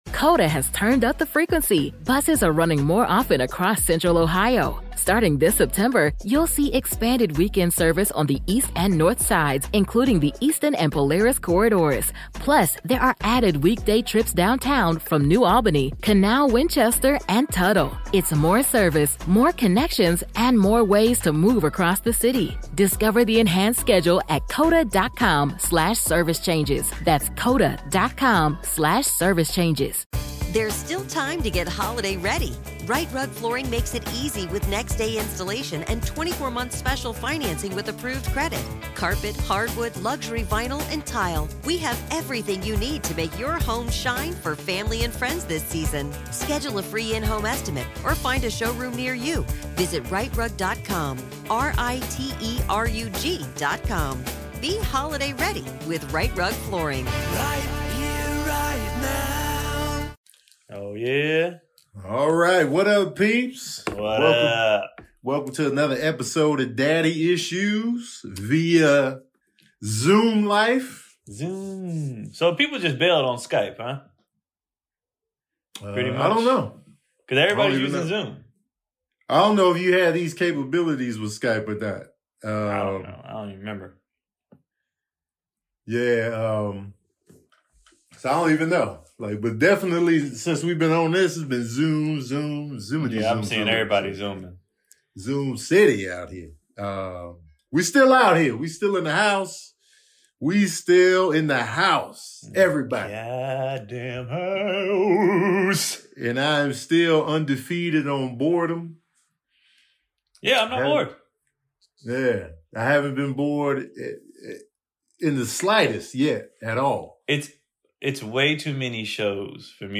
Daddy Issues Podcast is back again from the safety of our own homes.